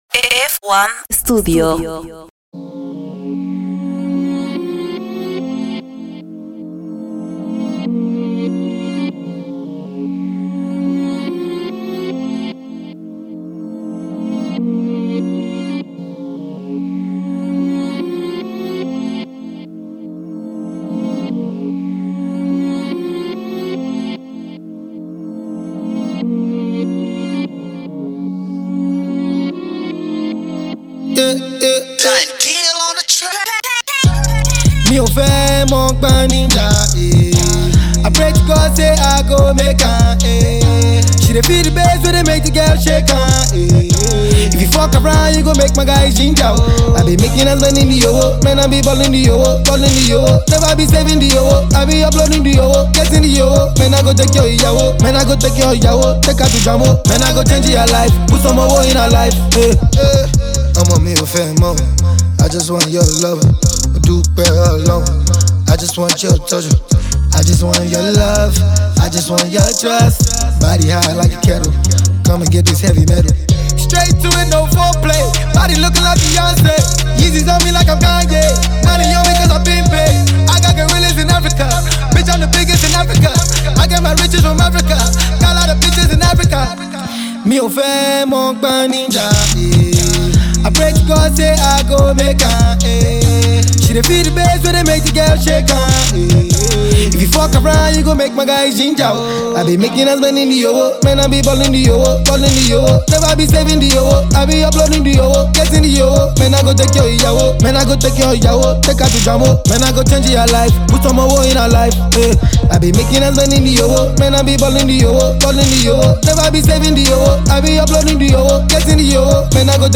Trap